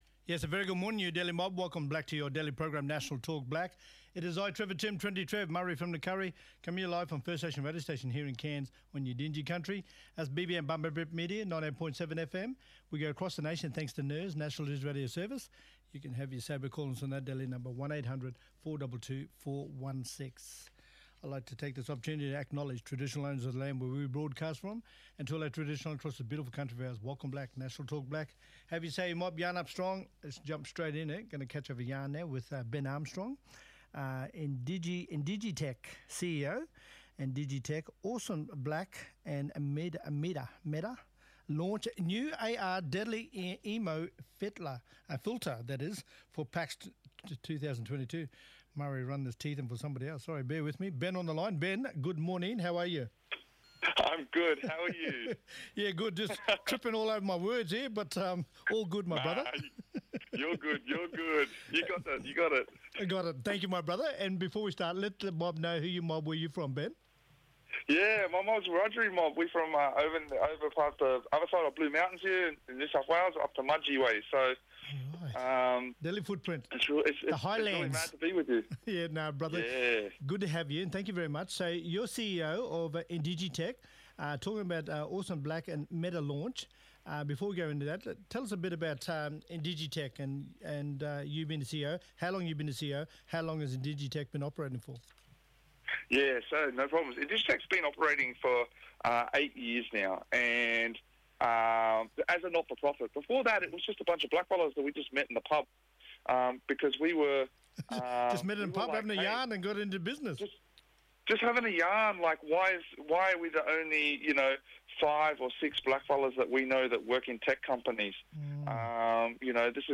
We would also like to thank the Community Broadcasting Foundation and National Indigenous Australians Agency for their support here at BBM 98.7FM. Make sure to tune in Monday-Friday 11am-12pm QLD Time to stay up to date with the latest national events.